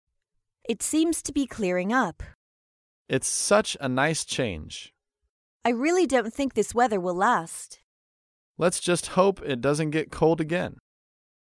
在线英语听力室高频英语口语对话 第90期:天气变好的听力文件下载,《高频英语口语对话》栏目包含了日常生活中经常使用的英语情景对话，是学习英语口语，能够帮助英语爱好者在听英语对话的过程中，积累英语口语习语知识，提高英语听说水平，并通过栏目中的中英文字幕和音频MP3文件，提高英语语感。